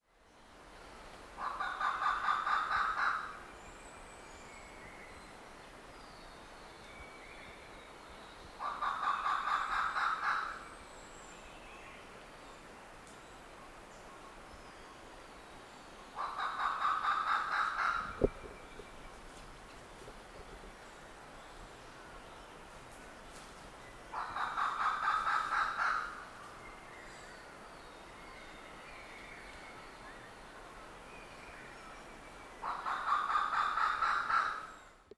Крик индийского павлиньего фазана